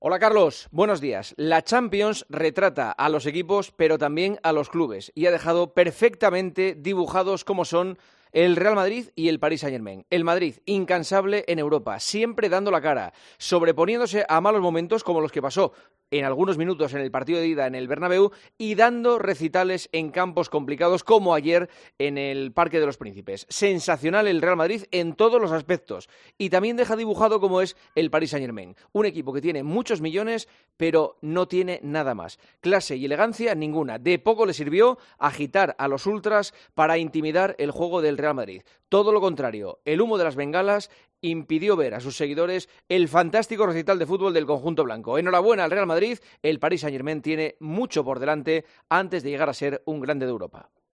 AUDIO: Escucha el comentario del director de 'El Partidazo de COPE', Juanma Castaño, en 'Herrera en COPE'